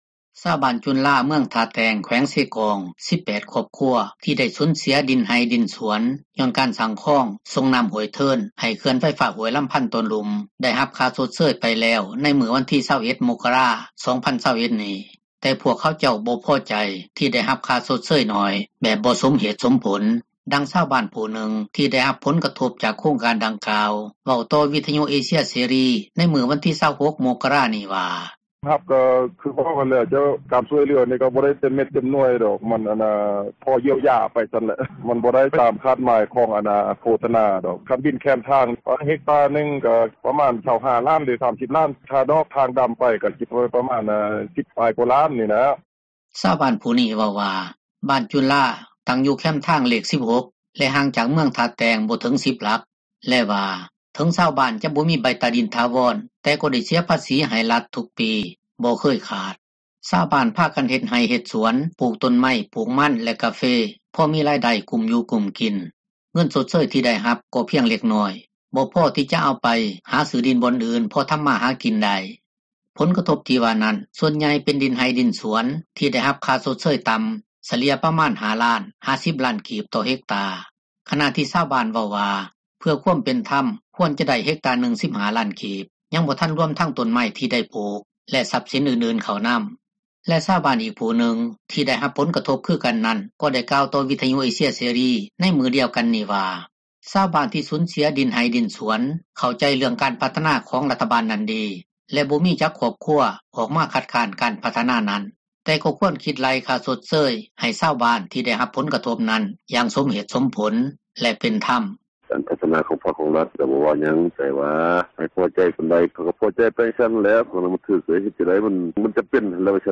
ຊາວບ້ານຈຸນລາ ເມືອງທ່າແຕງ ແຂວງເຊກອງ 18 ຄອບຄົວ ທີ່ໄດ້ສູນເສັຽດິນໄຮ່່ດິນສວນ ຍ້ອນການສ້າງຄອງສົ່ງນໍ້າຫ້ວຍເທີນ ໃຫ້ເຂື່ອນໄຟຟ້າຫ້ວຍລໍາພັນຕອນລຸ່ມ ໄດ້ຮັບຄ່າຊົດເຊີຍໄປແລ້ວ ໃນມື້ວັນທີ 21 ມົກກະຣາ 2021 ນີ້ ແຕ່ພວກເຂົາເຈົ້າບໍ່ພໍໃຈ ທີ່ໄດ້ຮັບຄ່າຊົດເຊີຍໜ້ອຍ ແບບບໍ່ສົມເຫດສົມຜົລ ດັ່ງຊາວບ້ານຜູ້ນຶ່ງ ທີ່ໄດ້ຮັບ ຜົລກະທົບຈາກໂຄງການດັ່ງກ່າວ ເວົ້າຕໍ່ວິທຍຸ ເອເຊັຽເສຣີໃນມື້ວັນທີ 26 ມົກກະຣາ ນີ້ວ່າ: